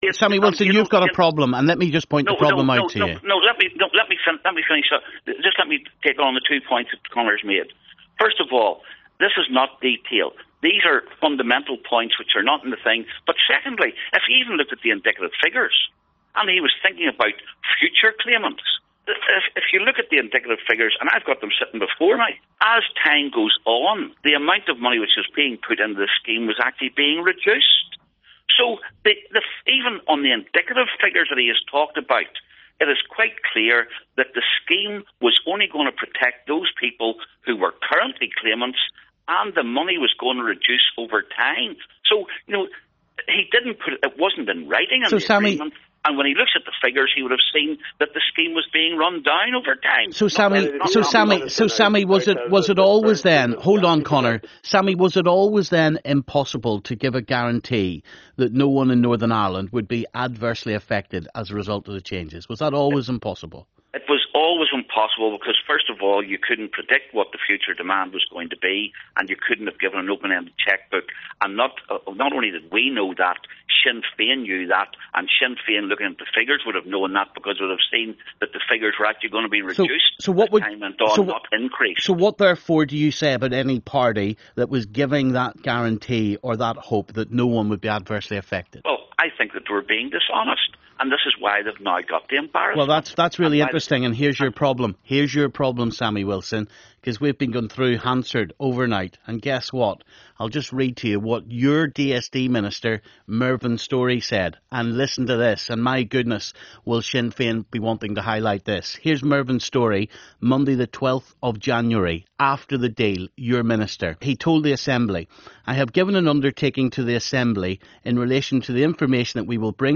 Conor Murphy and Sammy Wilson debate Stormont welfare breakdown. [Part 2]